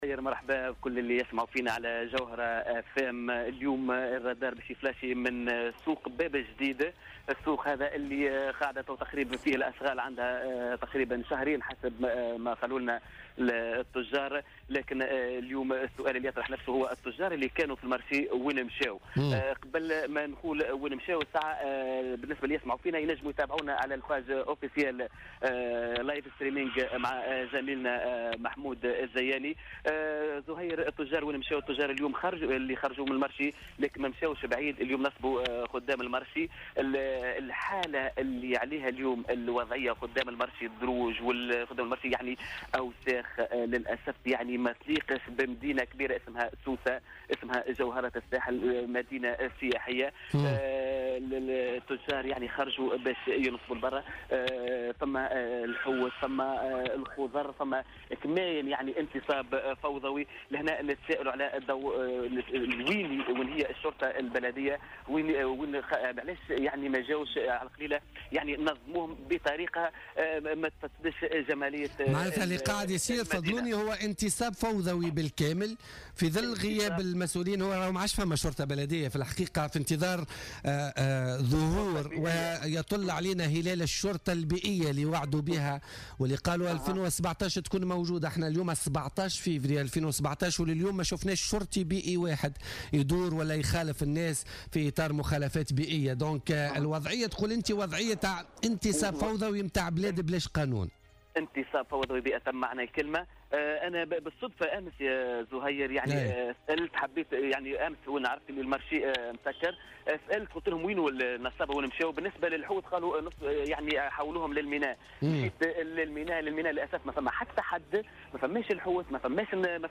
عاين الرادار اليوم الجمعة 17 فيفري 2017 نقص في الحركية بالسوق البلدية باب الجديد رغم مواصلة عدد من التجار الانتصاب عشوائيا في ذلك المكان .